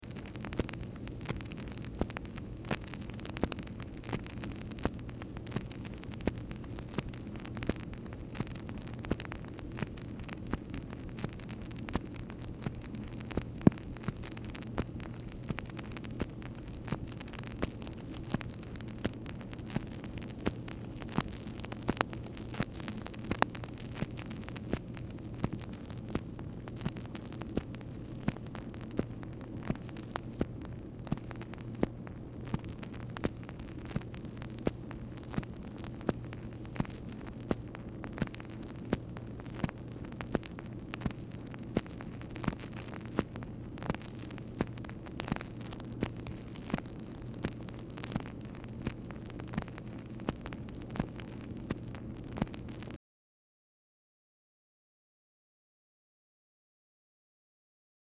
MACHINE NOISE
Dictation belt